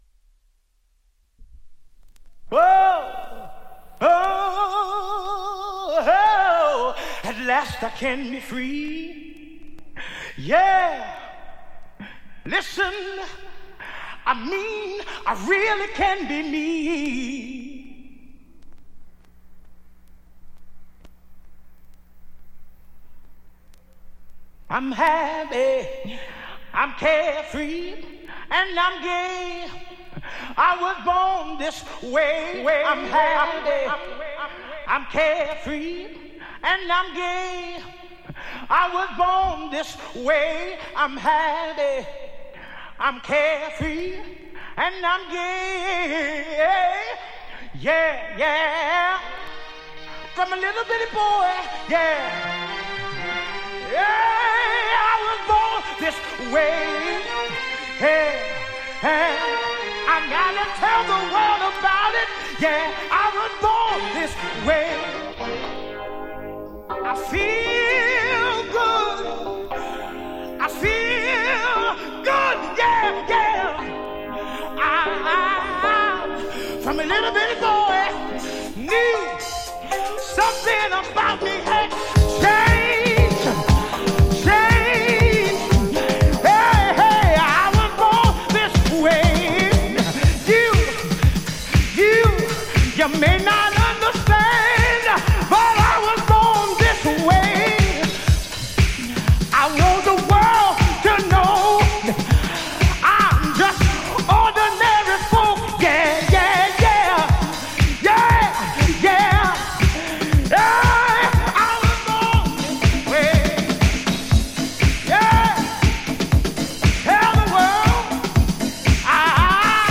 ジャンル(スタイル) DISCO / GARAGE / DANCE CLASSIC / REISSUE